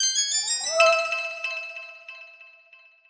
Special & Powerup (3).wav